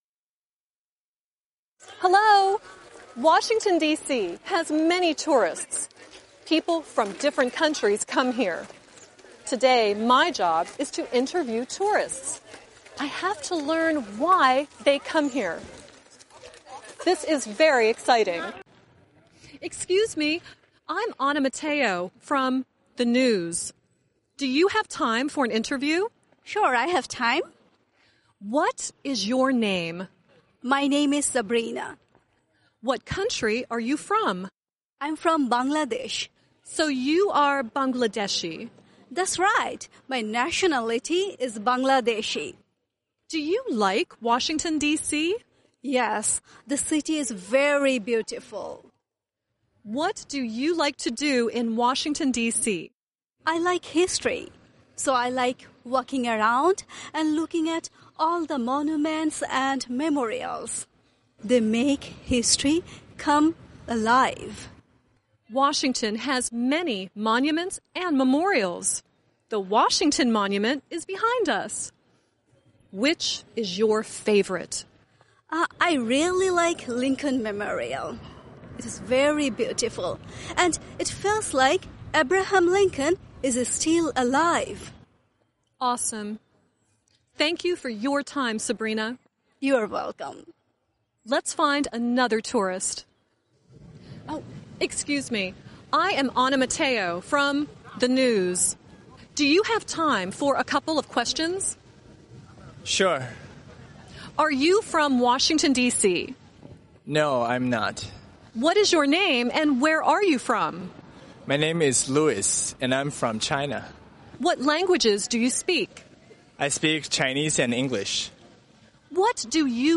Phần đối thoại (Conversation)